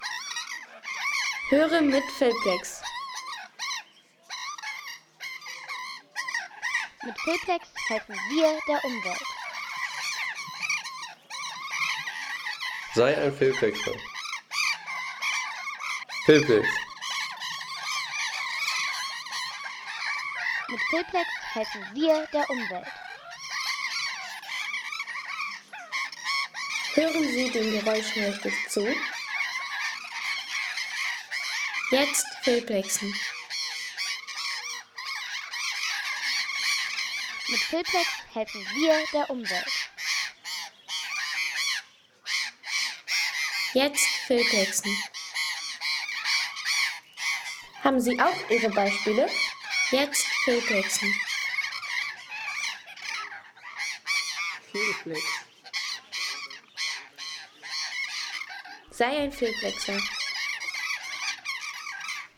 Diskussionen der Felsensittiche
Die lauten Diskussionen der Felsensittiche.